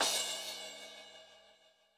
RX5 CRASH 2.wav